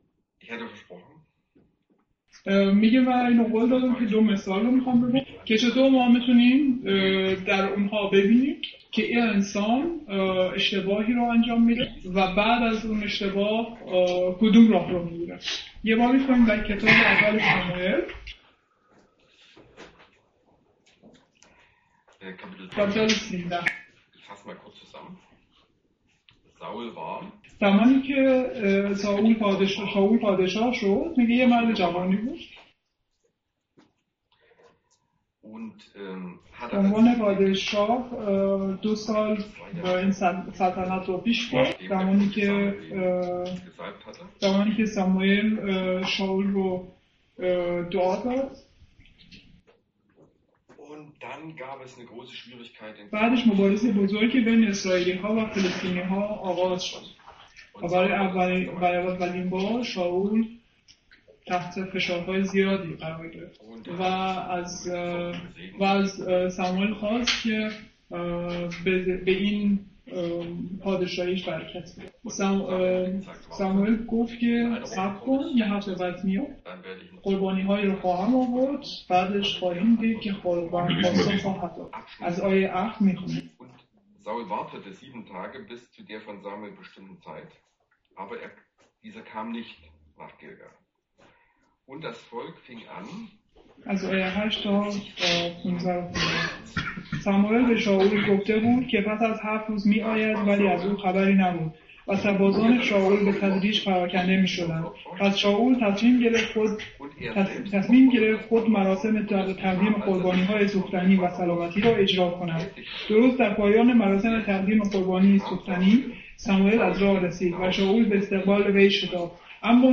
vergleicht in seiner Predigt die biblischen Personen Saul und David | Übersetzung in Farsi